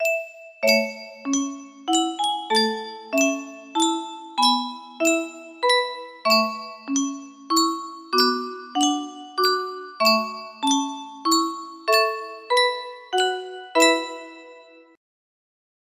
Yunsheng Music Box - To God Be the Glory Y883 music box melody
Yunsheng Music Box - To God Be the Glory Y883
Full range 60